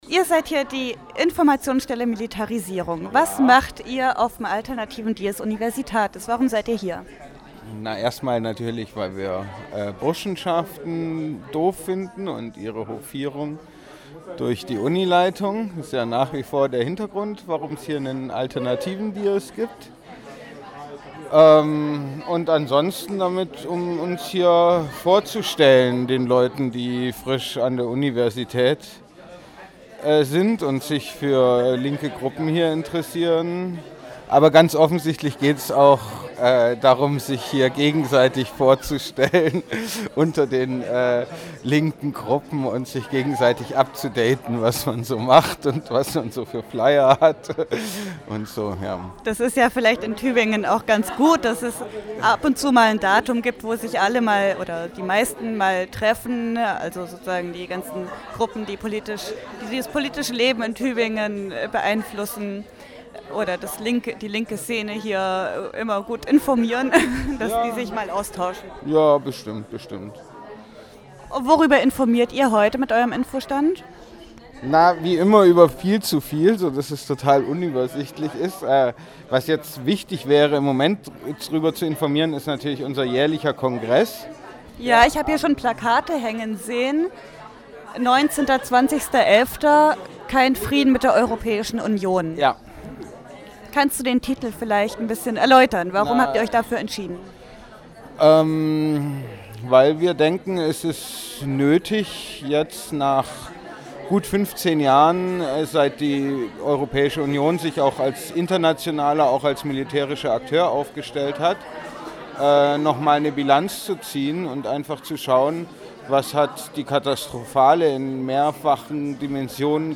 Hier findet ihr einige kurze Interviews und Vorstellungen von Gruppen, die sich am 20.10. auf dem Markt der möglichen Alternativen präsentiert haben.
IMI Kurzinterview
67066_Informationsstelle_Militarisierung_Kurzinterview.mp3